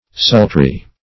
Sultry \Sul"try\ (s[u^]l"tr[y^]), a. [Compar.